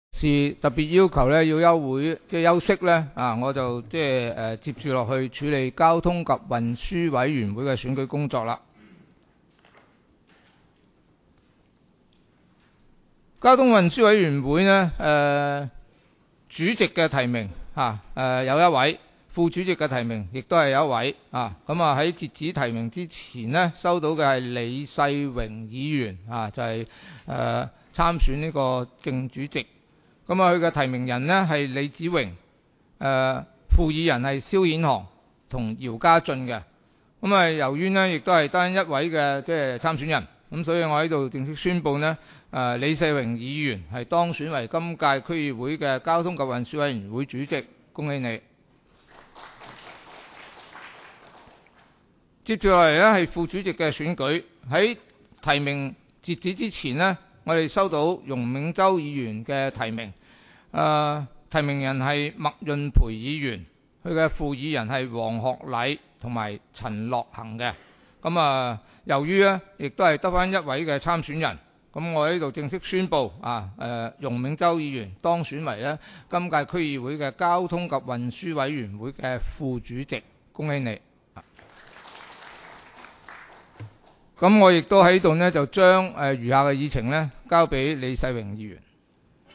委员会会议的录音记录
交通及运输委员会第一次会议 日期: 2016-01-27 (星期三) 时间: 下午4时20分 地点: 沙田区议会会议室 议程 讨论时间 I. 选举委员会主席及副主席 00:01:29 II.